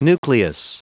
Prononciation du mot nucleus en anglais (fichier audio)
Prononciation du mot : nucleus